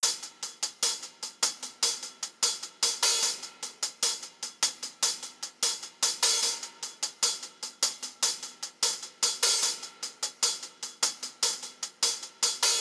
My Tears Hi Hat Loop.wav